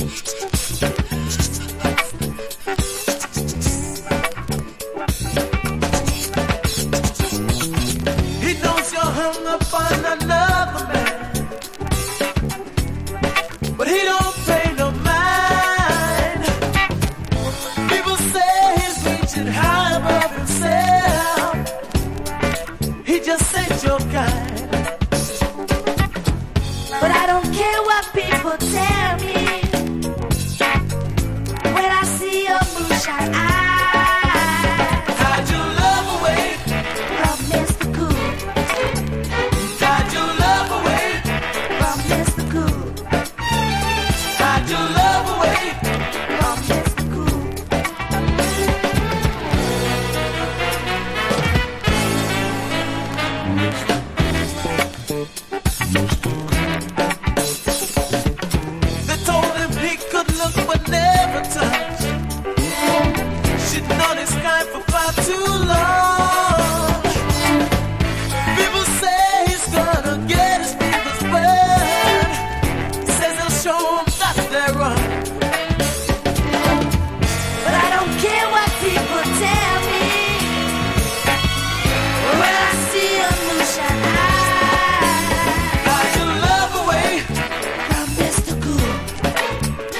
BRADY BUNCHにも通じるキッズソウル的ポップネスと爽やかさが溢れ出てます。胸弾む楽曲が満載。